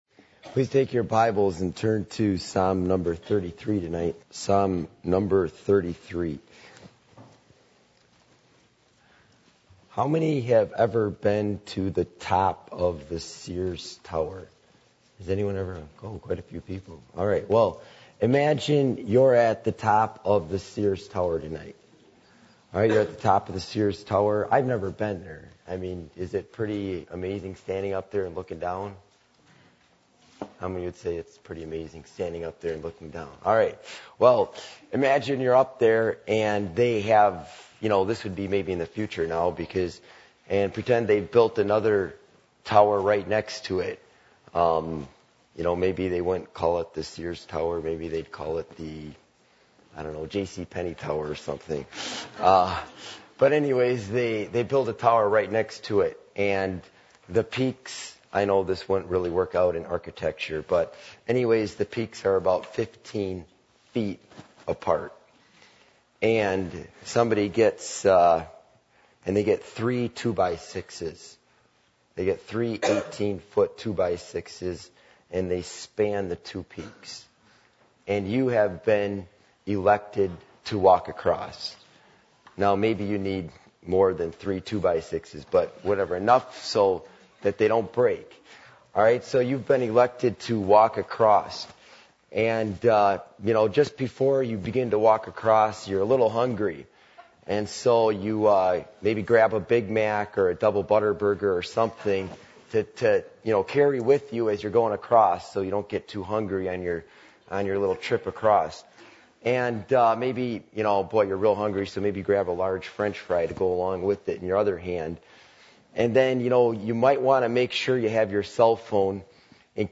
Psalm 33:1-22 Service Type: Midweek Meeting %todo_render% « The Important Perspective Of Prayer Communion With The Triune God